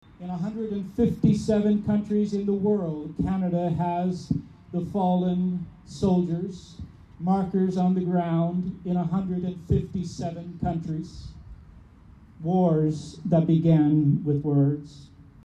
Members of the military, special guests, and residents paused Sunday afternoon at the Belleville Cenotaph to commemorate three pivotal battles and occasions in World Wars I and II.